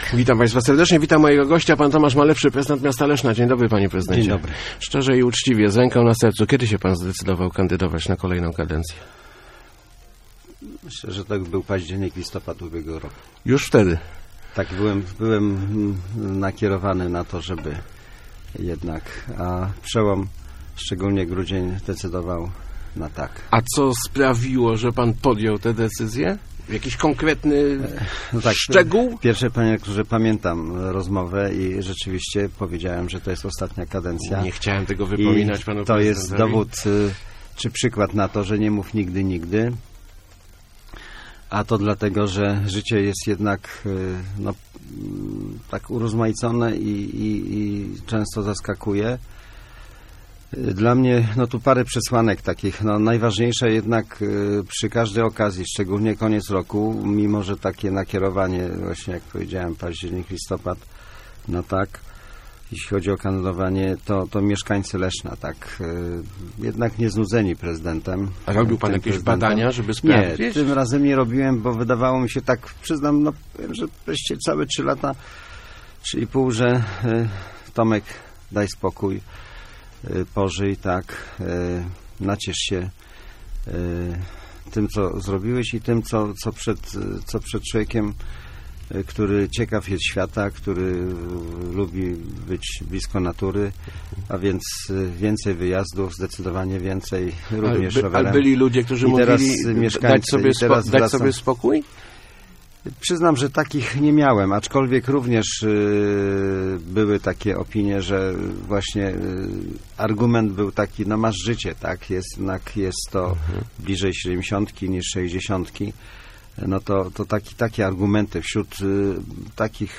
Wcale nie uważam, że nie mam z kim przegrać - mówił w Rozmowach Elki Tomasz Malepszy. Prezydent Leszna, który już zapowiedział staranie o kolejną kadencję przyznał, że ostateczną decyzję o starcie podjął pod koniec ubiegłego roku.